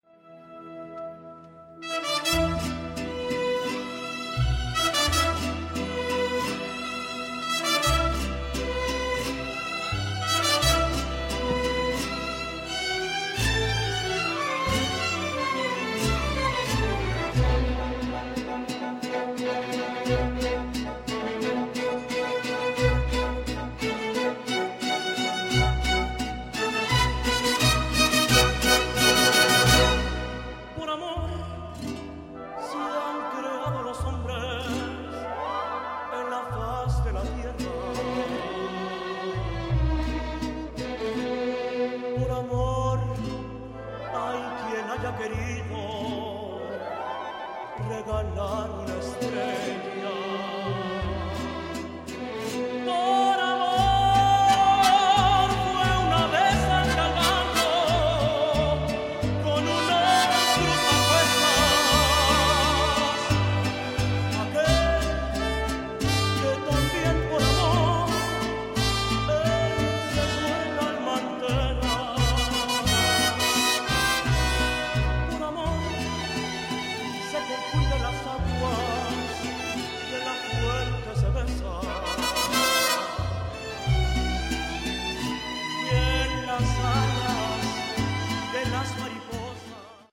This Vancouver Mariachi group is one of Canada’s outstanding Mexican Music Ensembles. The band was founded in 1992 by a mixture of Mexican and Canadian musicians to blend the traditional styling with the classical panache, from soft romantic ballads to the lively ranchero songs.